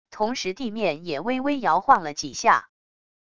同时地面也微微摇晃了几下wav音频生成系统WAV Audio Player